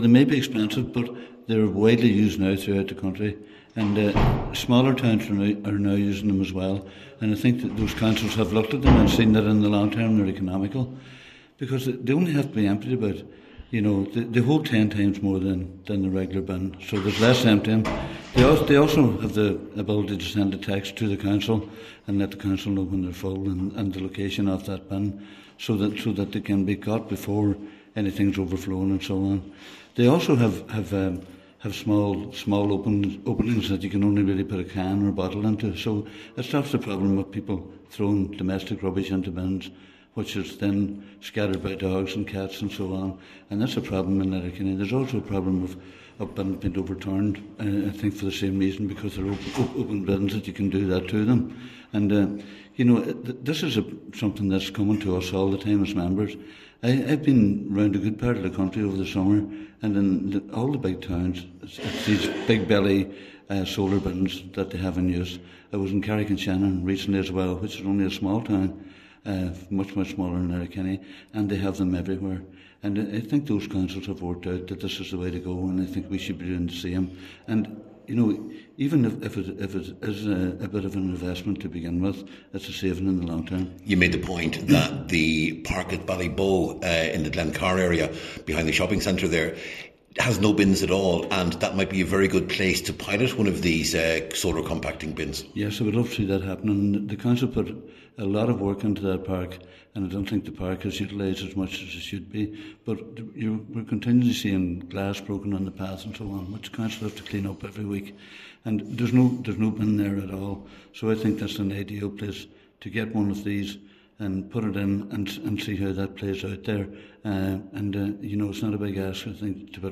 Cllr Jimmy Kavanagh told a Municipal District meeting there are too many complaints about the lack of bins in the town.
Calling for a review of the location of bins, Cllr Kavanagh said it’s time to look seriously at the compacting option: